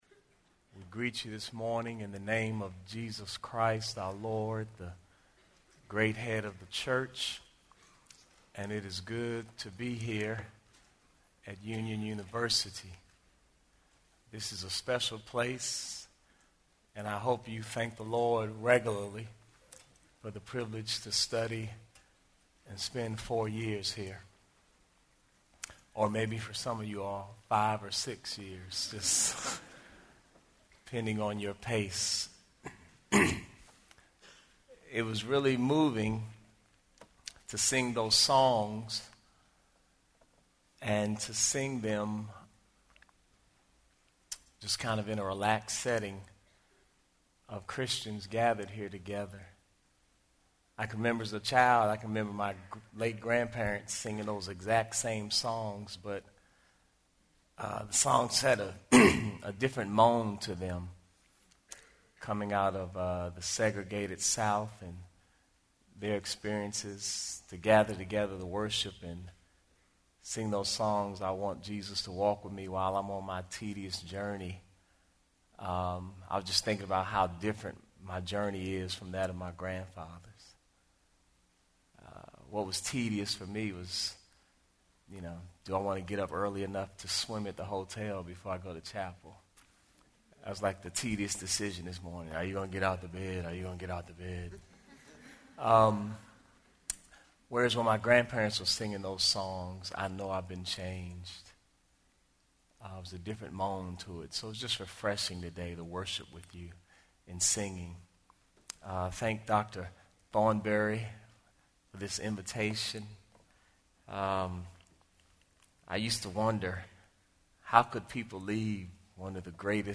Union University, a Christian College in Tennessee
Chapel